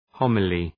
Προφορά
{‘hɒməli:}